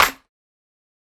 BattleCatSwaggerClap.wav